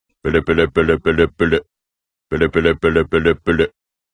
голосовые , из мультфильмов